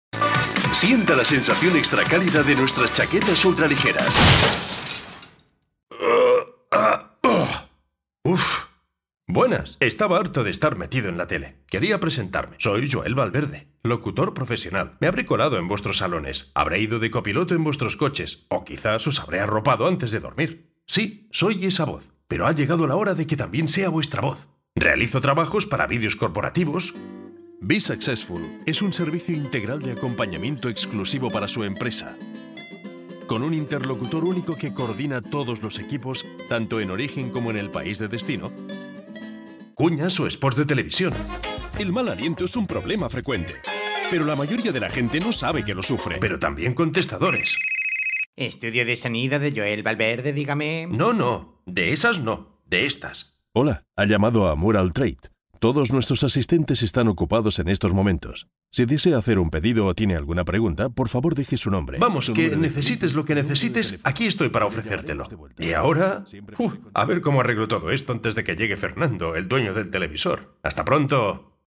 Native speaker Male 50 lat +
Nagranie lektorskie